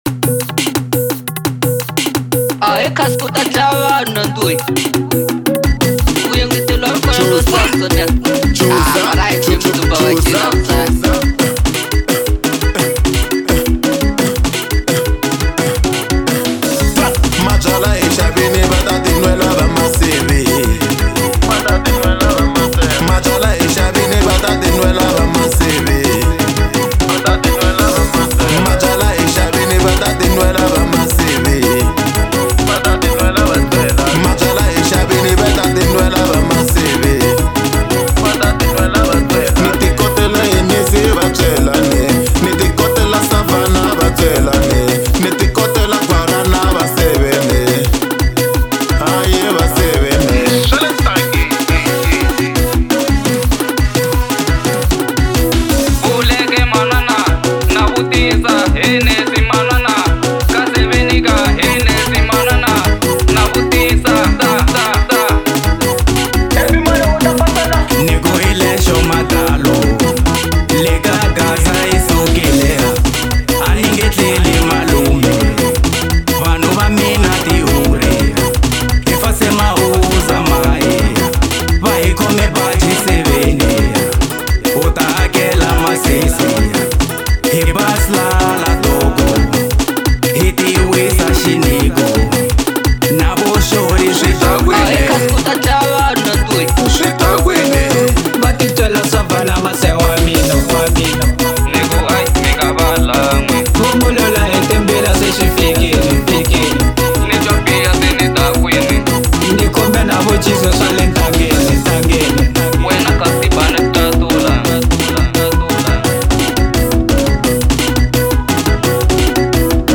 04:35 Genre : Xitsonga Size